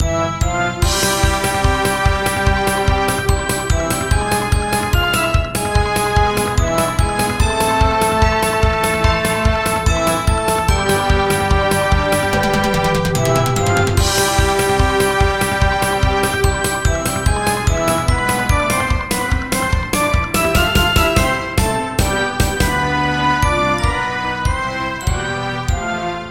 洋上を疾走する帆船をイメージした疾走感のある爽やかなメロディー。
ショートループ